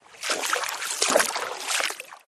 water-wade-07.ogg